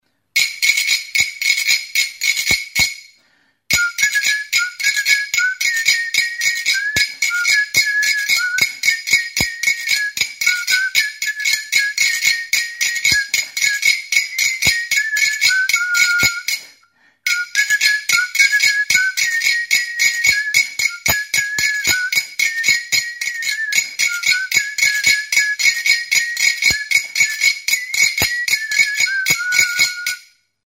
Idiophones -> Scraped
Recorded with this music instrument.
Azal zimurra duen anis botila da. Goilarearekin edo bestelako tresnekin igurtzitzen da.